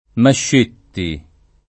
[ mašš % tti ]